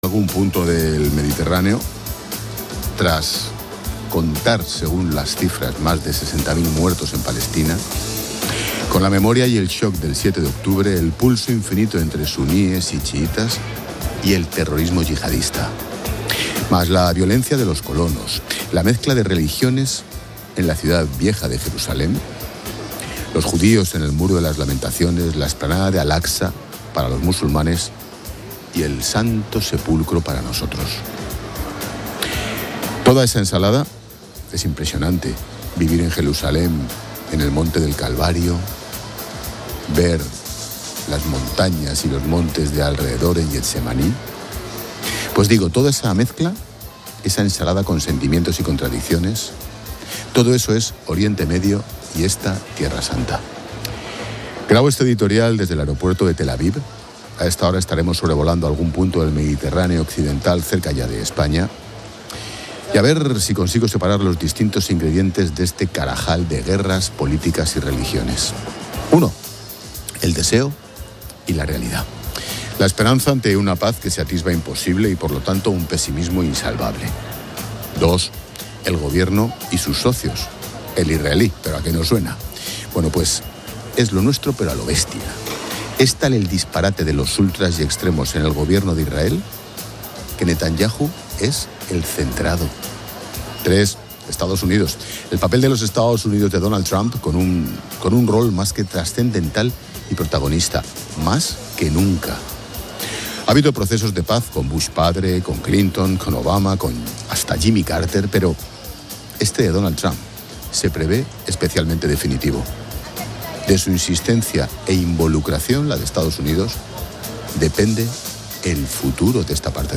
Se graba desde el aeropuerto de Tel Aviv.